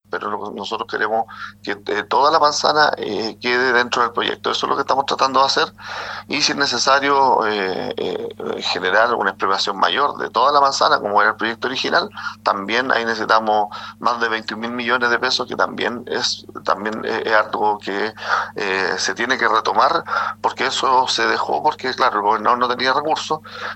cuna-platita-alcalde.mp3